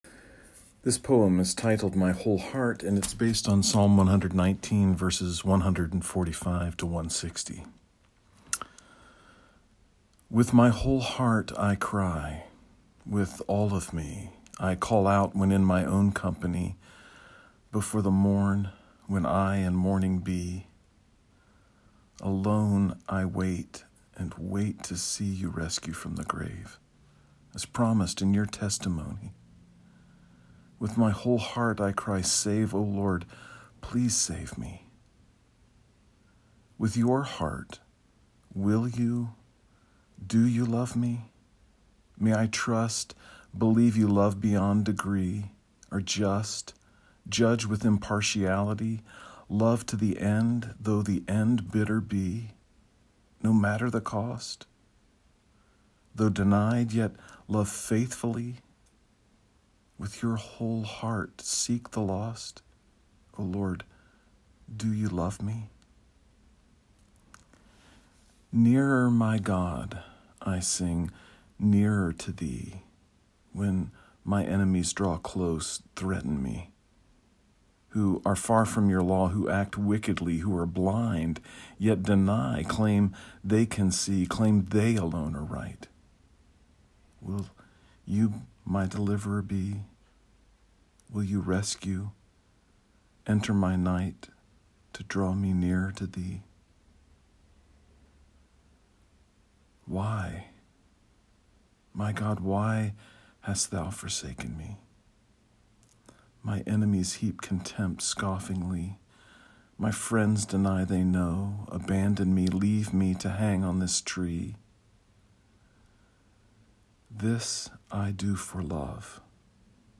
You may listen to me read the poem via the player below.